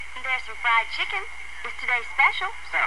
下列紅色部份為省音，已省去不唸；而字串連結為連音。